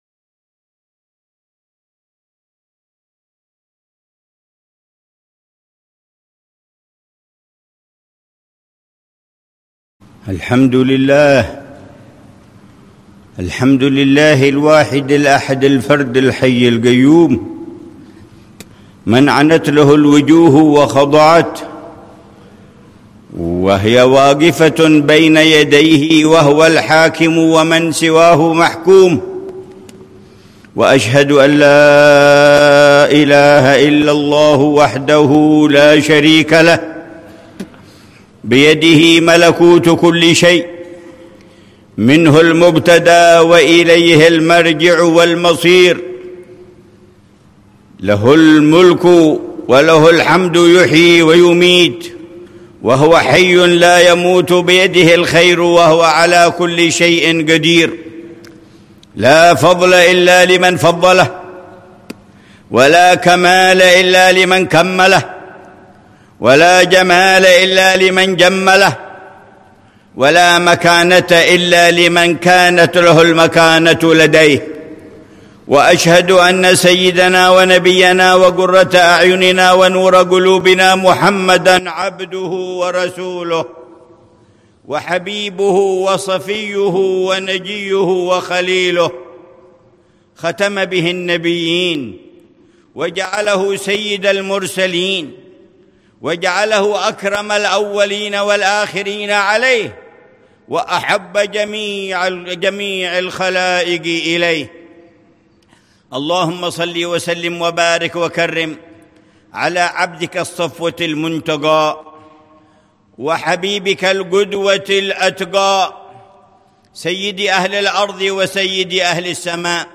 خطبة الجمعة للعلامة الحبيب عمر بن محمد بن حفيظ، في مسجد مقام سيدنا جعفر الطيار، في الكرك، الأردن، 6 ربيع الأول 1447هـ بعنوان: